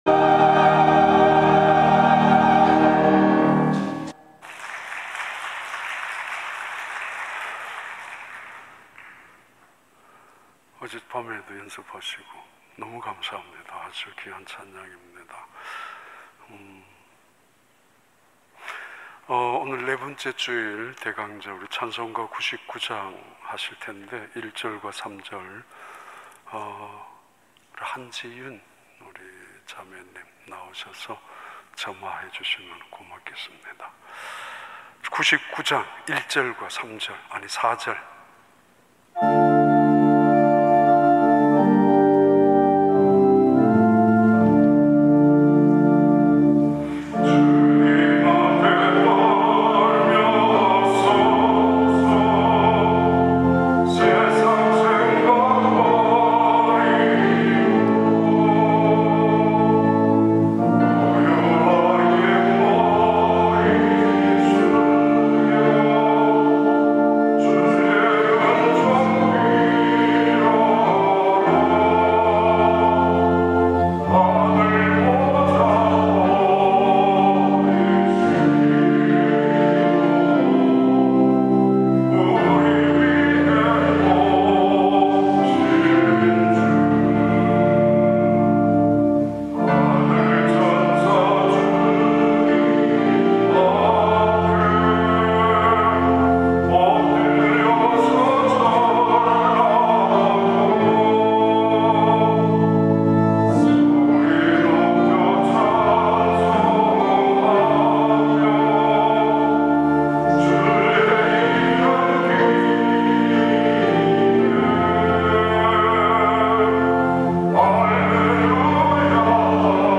2021년 12월 19일 주일 3부 예배